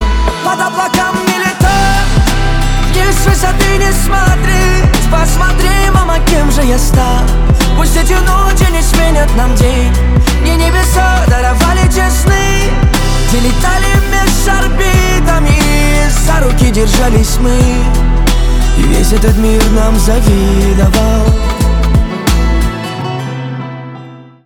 Кайфовая мелодия на звонок, битовая музыка с гитарой.